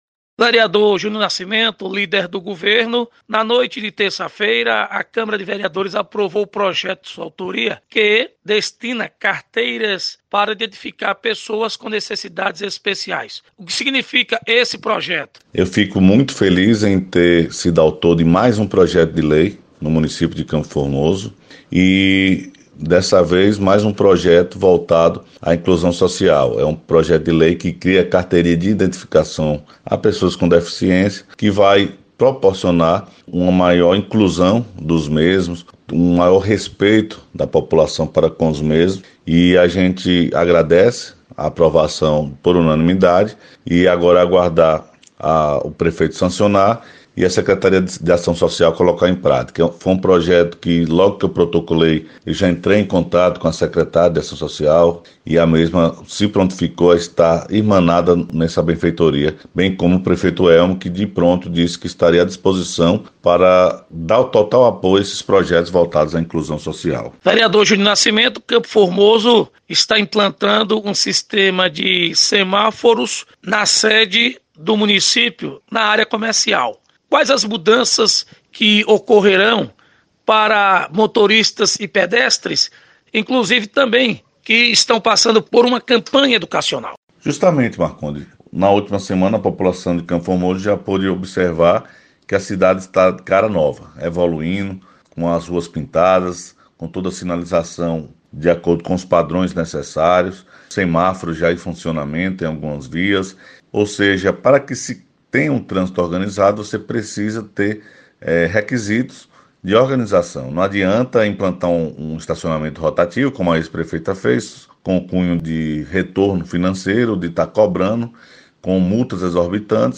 Entrevista: Vereadores